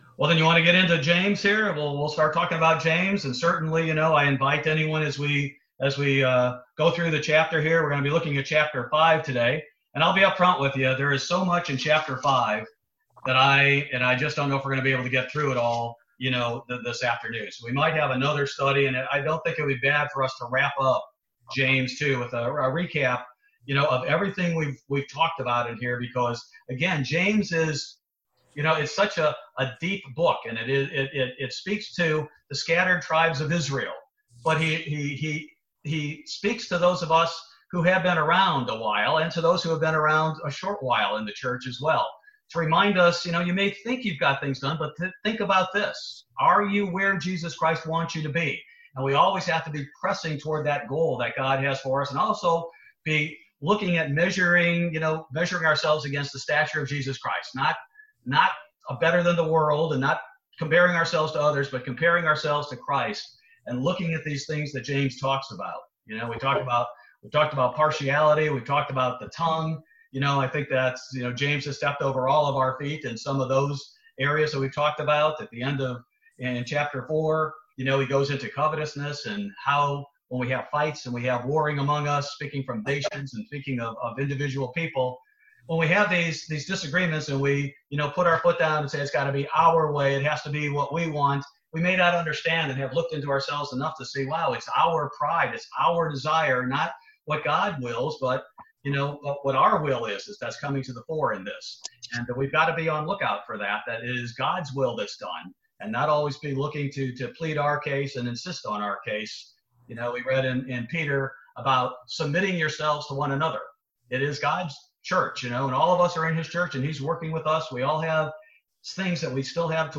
Weekly Bible Study covering the Book of James. This Bible Study focuses on James chapter 5.